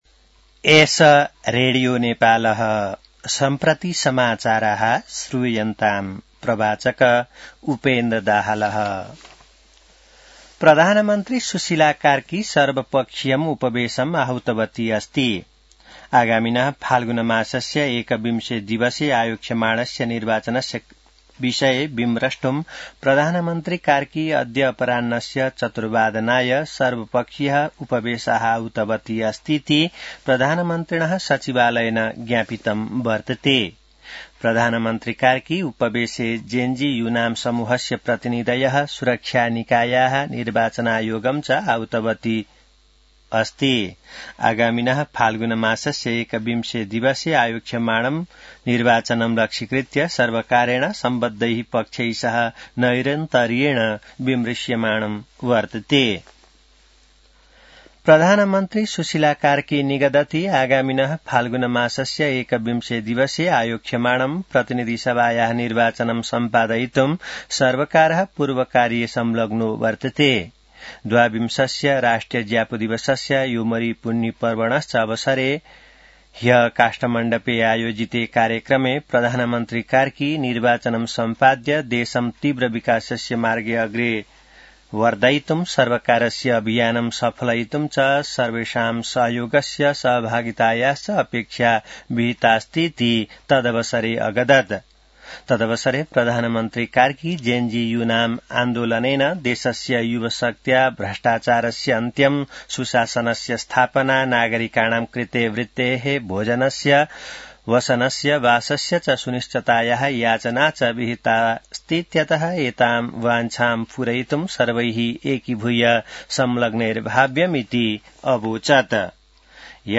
संस्कृत समाचार : १९ मंसिर , २०८२